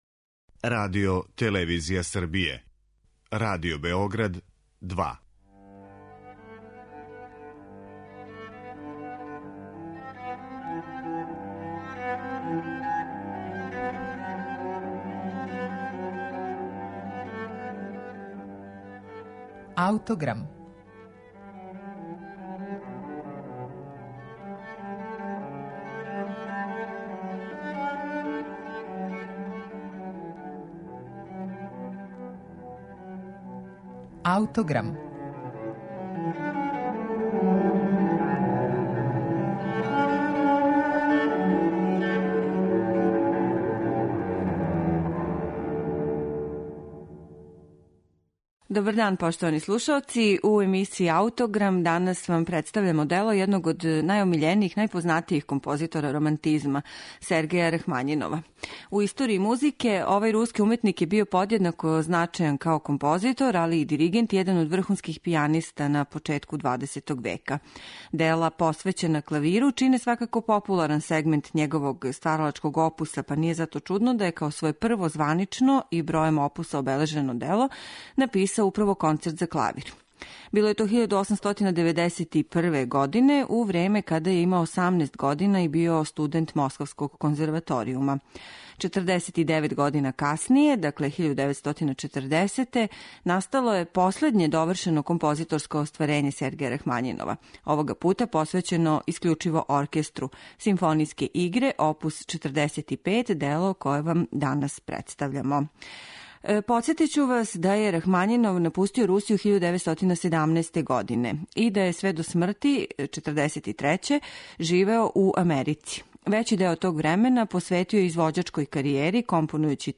Филаделфијским оркестром је дириговао Јуџин Орманди, а ми ћемо данас слушати оркестар Београдске филхармоније, под управом Бојана Суђића.